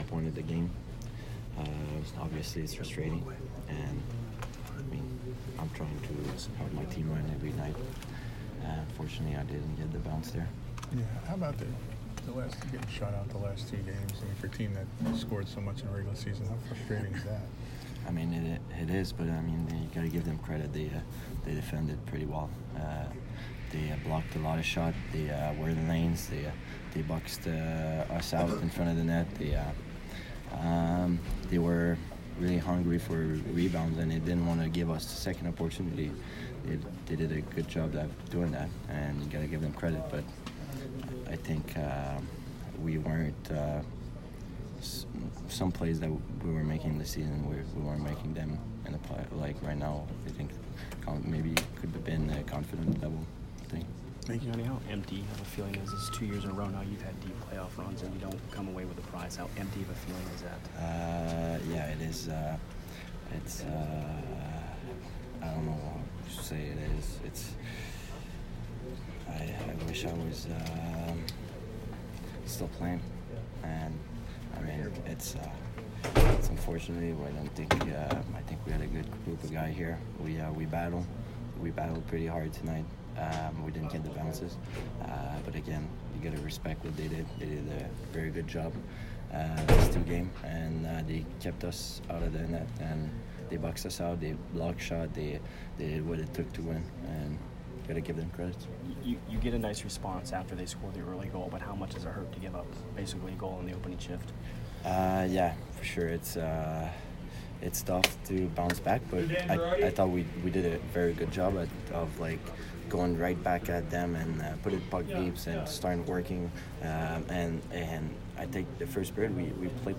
Yanni Gourde post-game 5/23